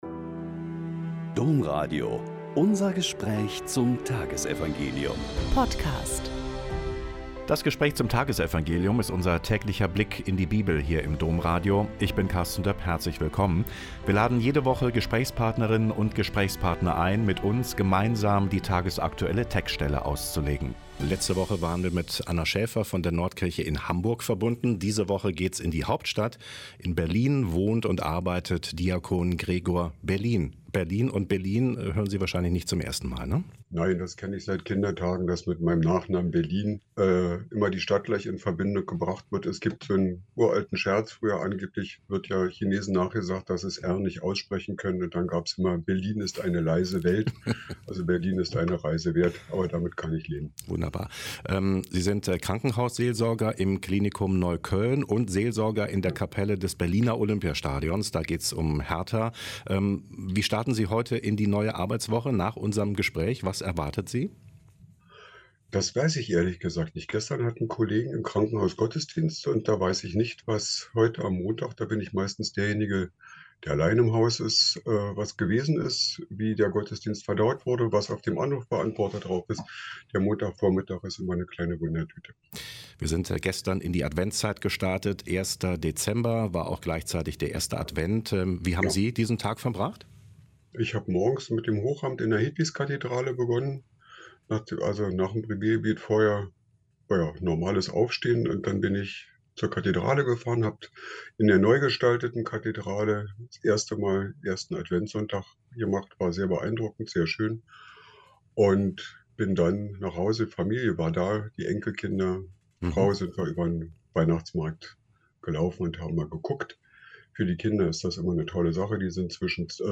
Mt 8,5-11 - Gespräch